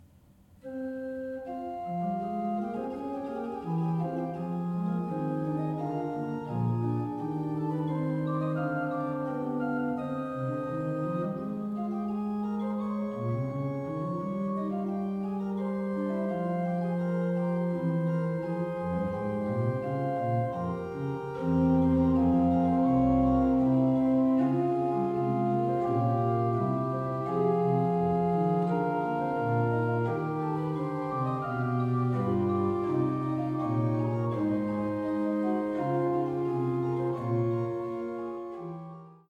Bourdon 16'
Montre 8'
Soubasse 16'